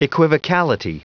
Prononciation du mot equivocality en anglais (fichier audio)
Prononciation du mot : equivocality
equivocality.wav